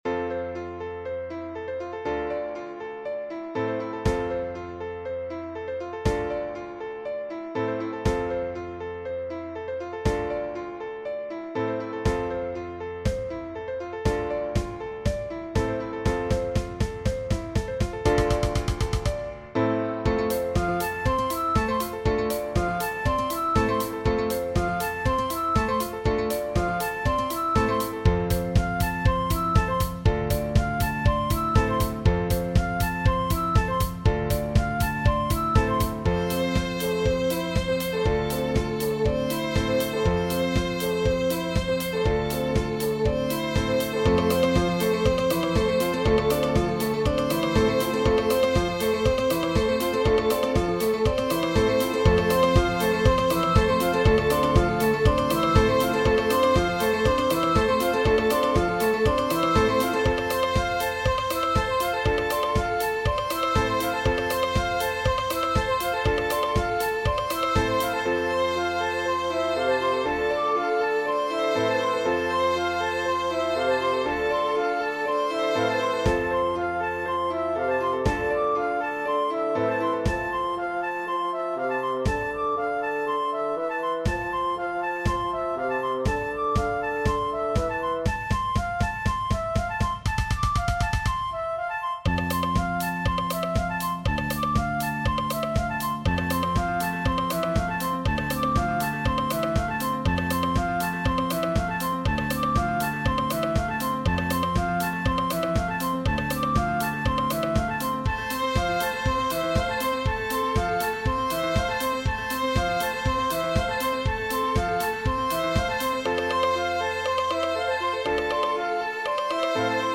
In mijn stuk hoor je ook de verschillende lagen die onderdeel zijn van het feestje.